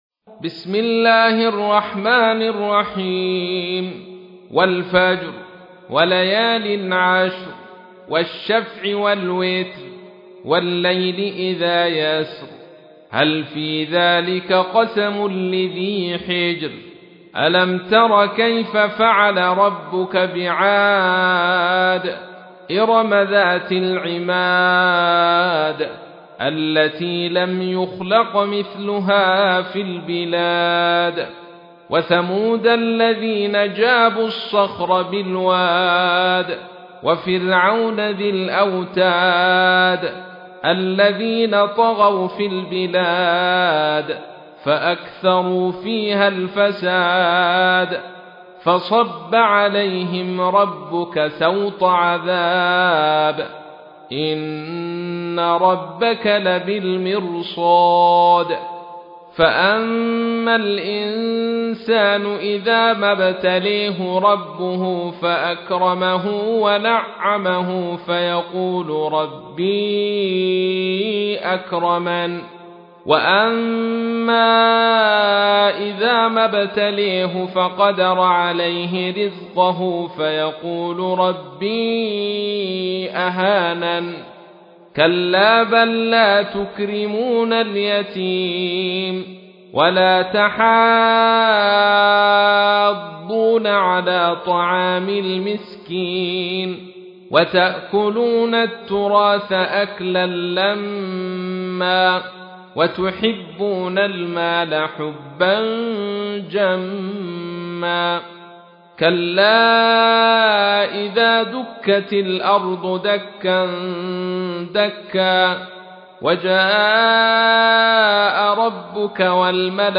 تحميل : 89. سورة الفجر / القارئ عبد الرشيد صوفي / القرآن الكريم / موقع يا حسين